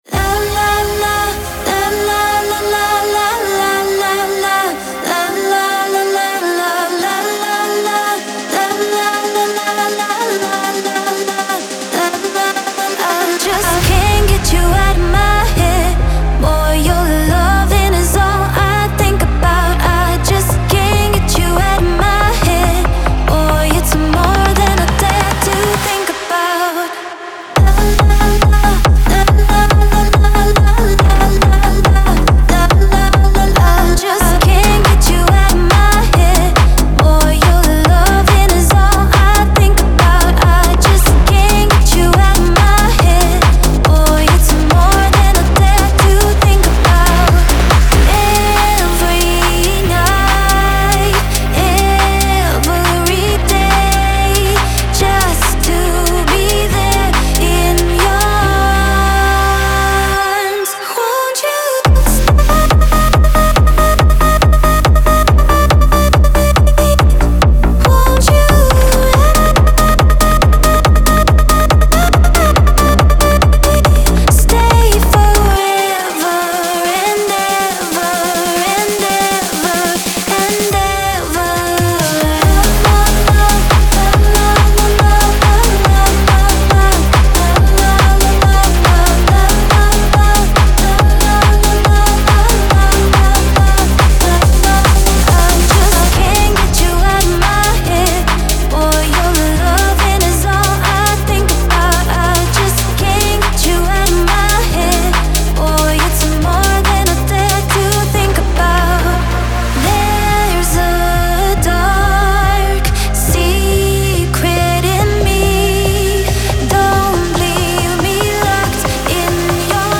• Жанр: Dance, EDM